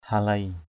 /ha-leɪ/